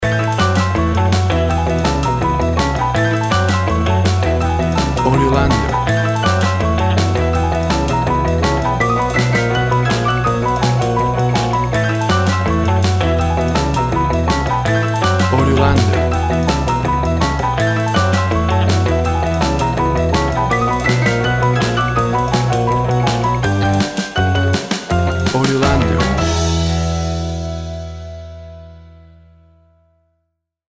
Sounds of blues and rock and roll from the 50’s.
Tempo (BPM) 160